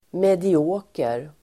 Ladda ner uttalet
medioker adjektiv, mediocre Uttal: [medi'å:ker]